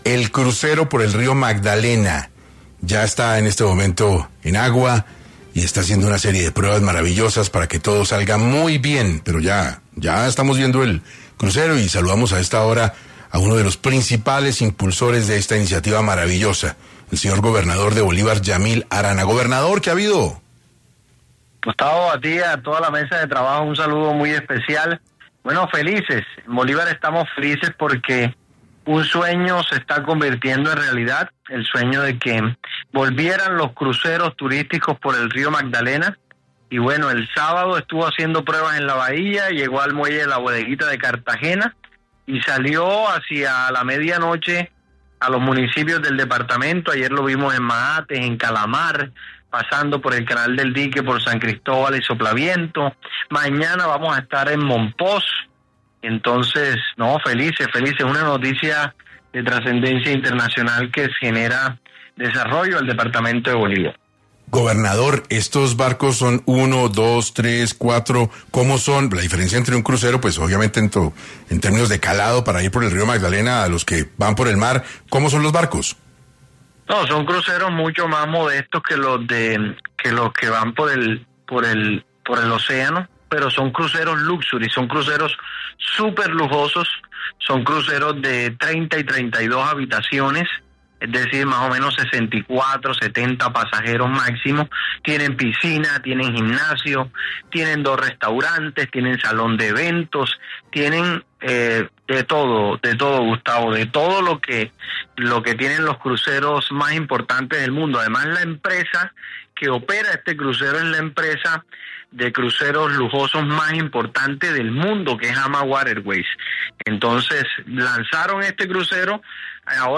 En 6AM de Caracol Radio estuvo el gobernador de Bolívar Yamil Arana, quien habló sobre el nuevo crucero que transita por el río Magdalena que tiene entre 30 y 32 habitaciones lujosas